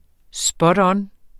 Udtale [ ˈsbʌdˈʌːn ]